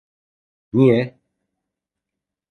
Pronunciado como (IPA)
/nije/